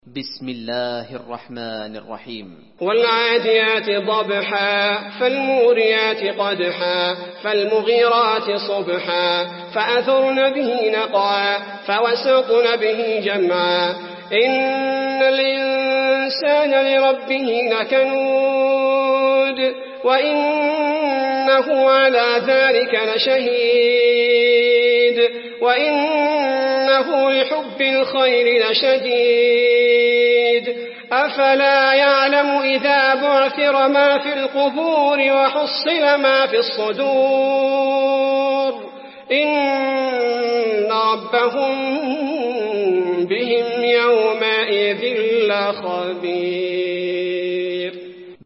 المكان: المسجد النبوي العاديات